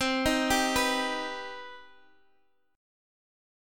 CmM7 Chord